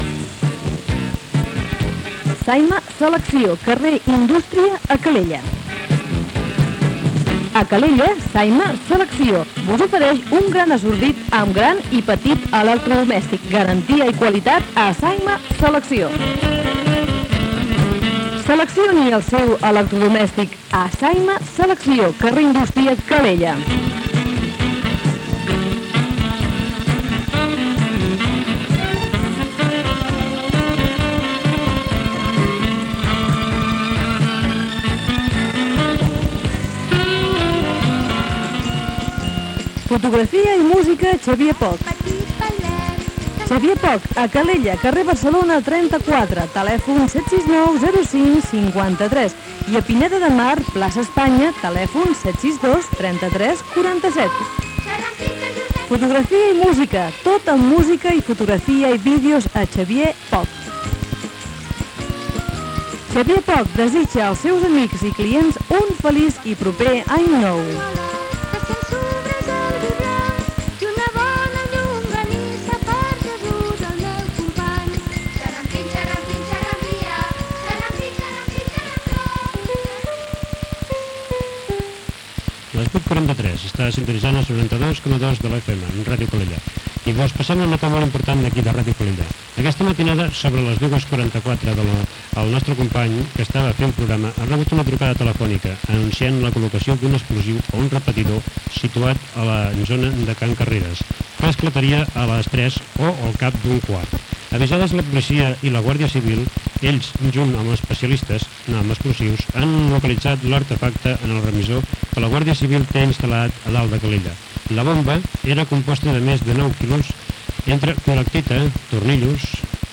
Publicitat, indicatiu, avís de la desactivació d'un explosiu en un repetidor de la Guàrdia Civil.
Informatiu
FM